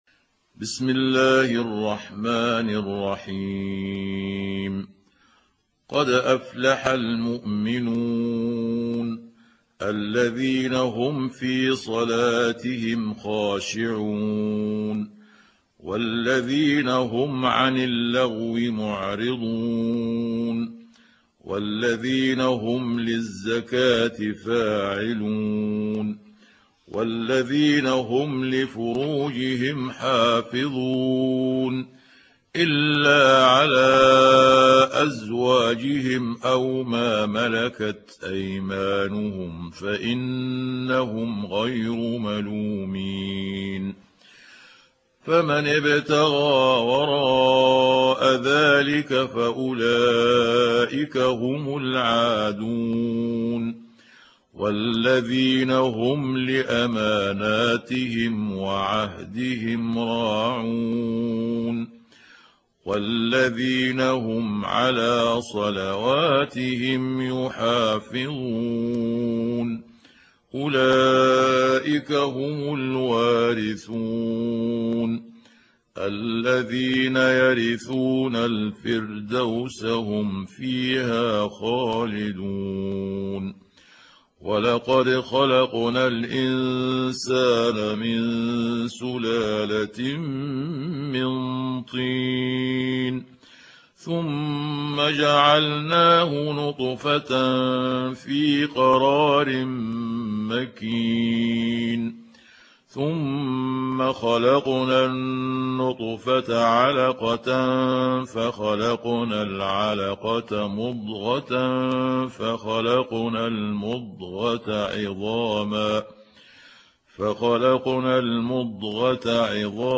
تحميل سورة المؤمنون mp3 بصوت محمود عبد الحكم برواية حفص عن عاصم, تحميل استماع القرآن الكريم على الجوال mp3 كاملا بروابط مباشرة وسريعة